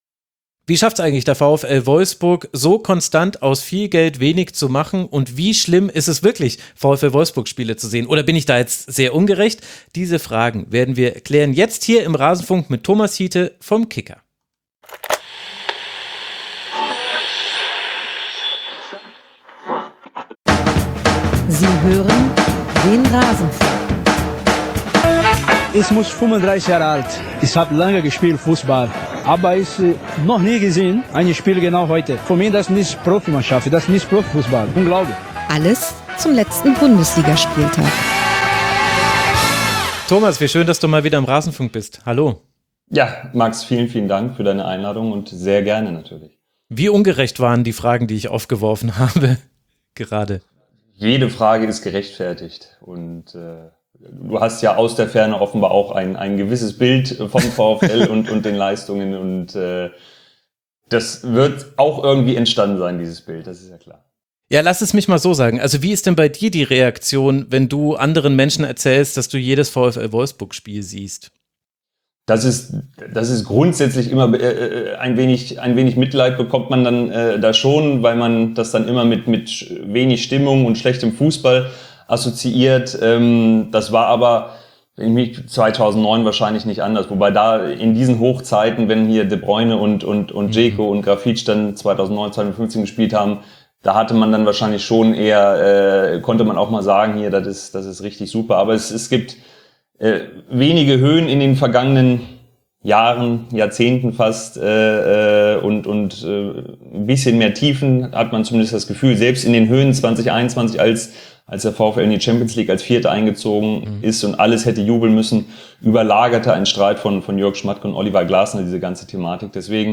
Ein Gespräch über schlechte Kommunikation, fehlende Identifikation, einen Friedhof und den möglichen Heilsbringer.